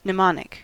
Ääntäminen
US : IPA : /nəˈmɑːnɪk/